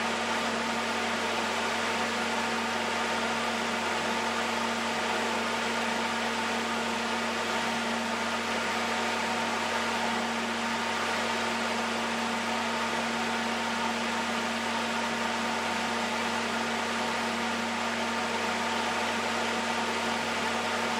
The frequency spike is at 250Hz at full speed, with the 160Hz frequency being also loud.
I have recorded the signals shown above, but please keep in mind that I’ve enabled Automatic Gain Control (AGC) to do so to make it easier for you to reproduce them.
100% Fan Speed